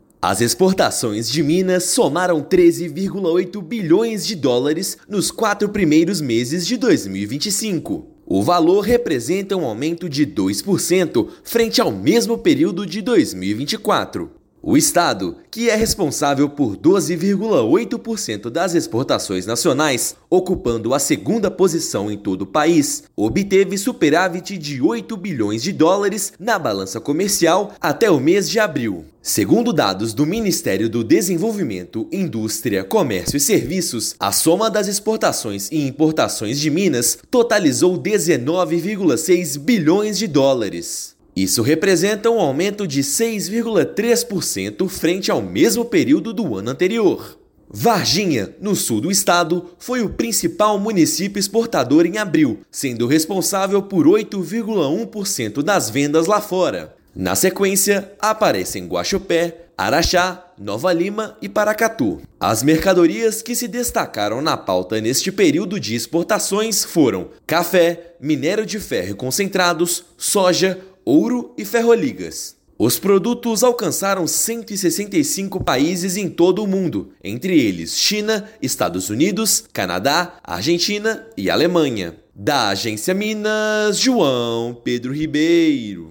Segundo maior exportador do Brasil, estado alcançou superávit de US$ 8 bilhões no período e manteve diversificação de produtos. Ouça matéria de rádio.